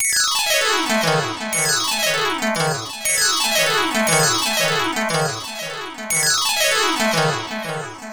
Gliss 2fer 3 Gliss.wav